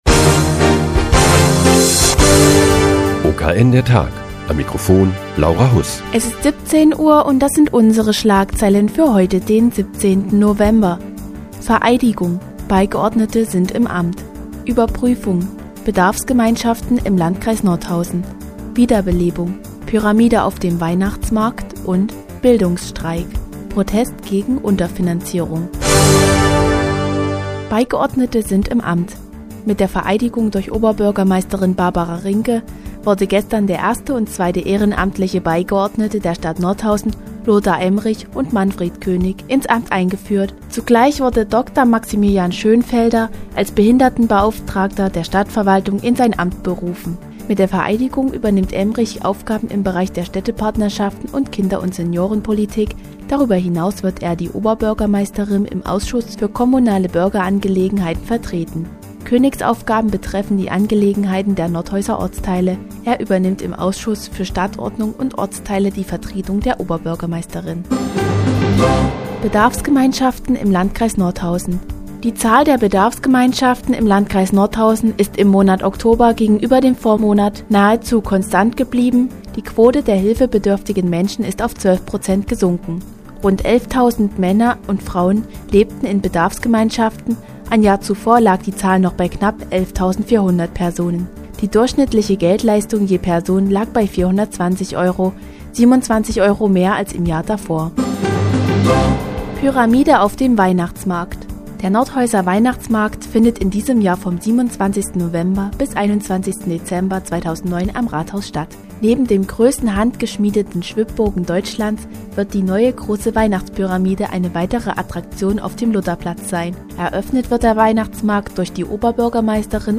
Die tägliche Nachrichtensendung des OKN ist nun auch in der nnz zu hören. Heute geht es um Bedarfsgemeinschaften im Landkreis Nordhausen, den Nordhäuser Weihnachtsmarkt und den Bildungsstreik Thüringer Studenten und Schüler.